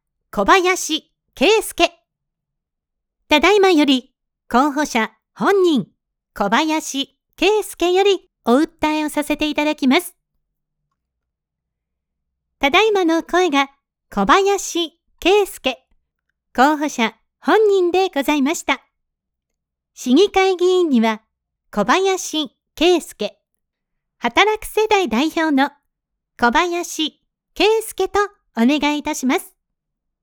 候補者ご紹介バージョン
選挙ウグイス嬢のしゃべり方は、特徴的です。